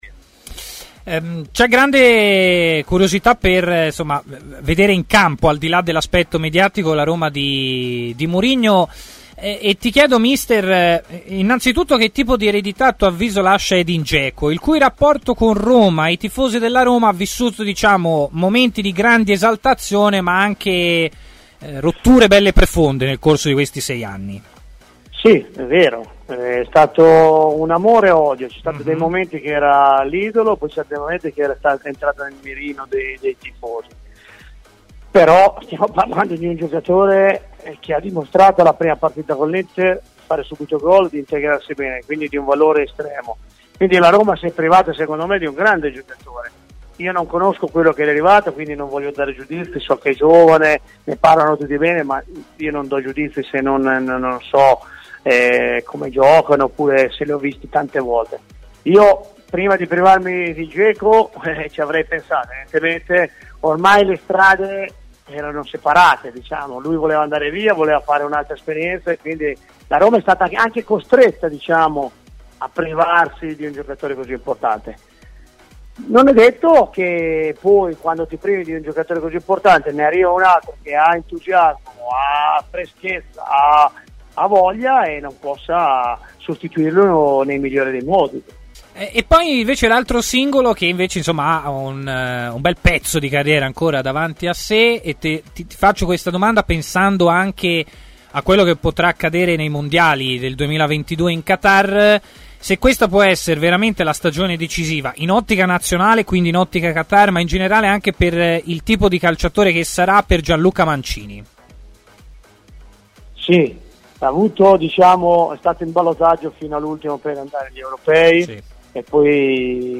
Nel corso della lunga intervista concessa ai microfoni di TMW Radio, Pierpaolo Bisoli si è soffermato sull'addio alla Roma di Edin Dzeko, ingaggiato dall'Inter per raccogliere la pesante eredità di Romelu Lukaku: "Il rapporto con la Roma è stato un amore-odio.